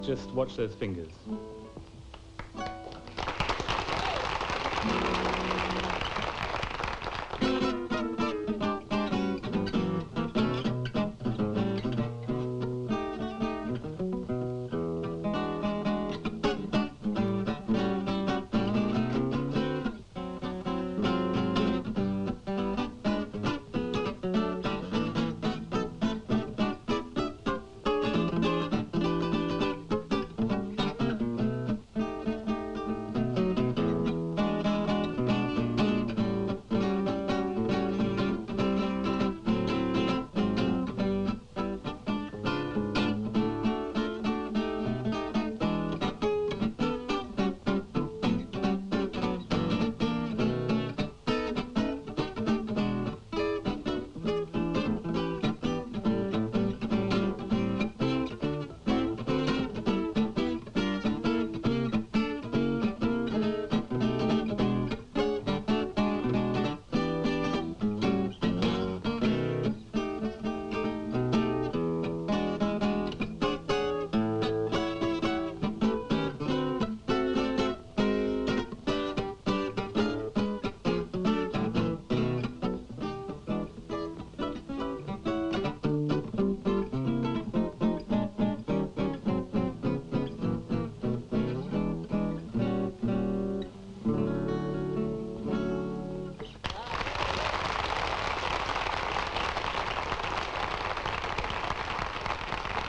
Kinda in the McDonough/Van Eps vein - very pretty!
I also hear a distinctive melody line which is unlike the bluegrass possibilities mentioned.